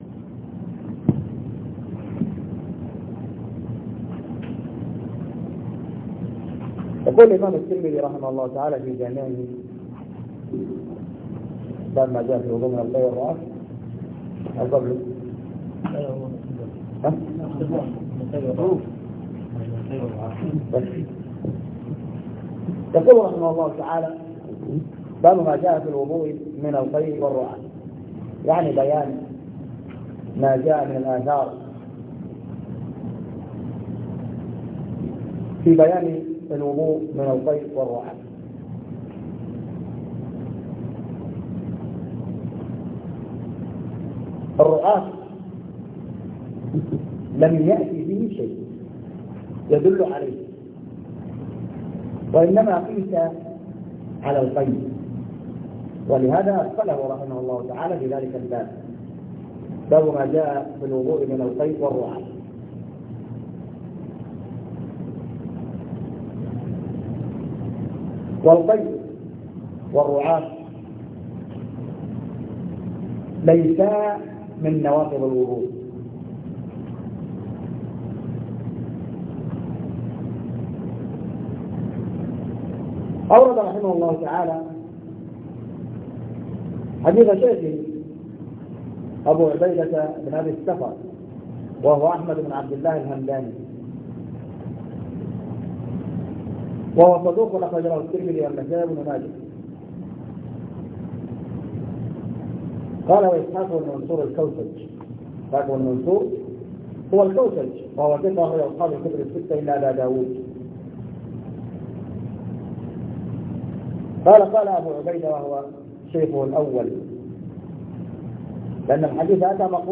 سلسلة دروس شرح جامع الترمذي